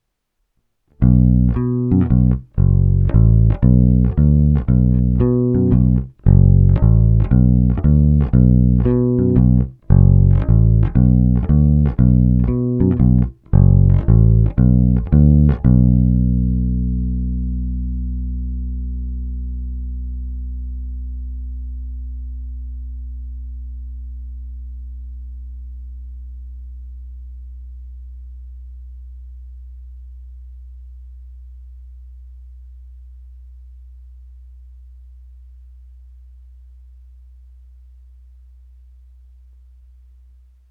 Zvukově je to klasický Precision, i s hlazenkami hraje naprosto parádně, a oproti mnoha jiným Precisionům má tenhle opravdu pevné, vrčivé Éčko.
Nahrávky rovnou do zvukovky, hráno nad snímačem s plně otevřenou tónovou clonou: